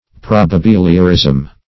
Probabiliorism \Prob`a*bil"i*o*rism\, n.